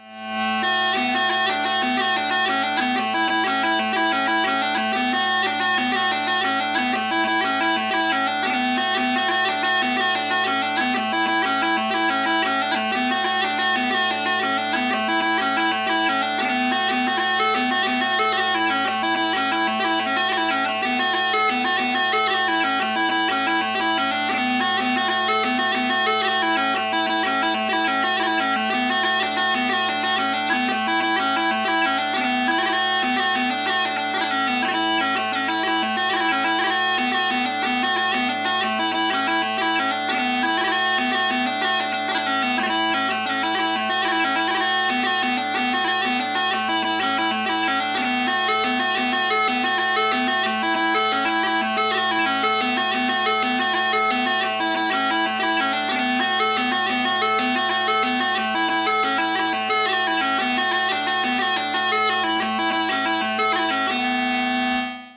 Jig